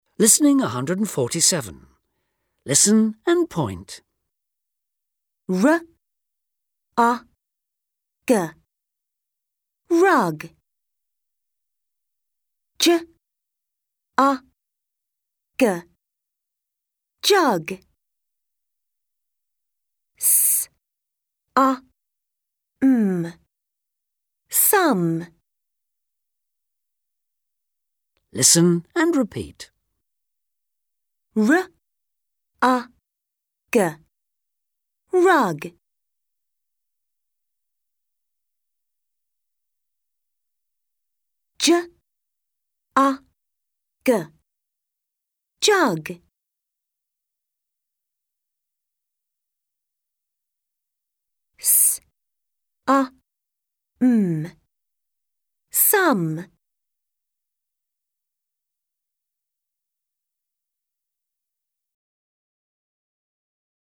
1) Listen, point and repeat.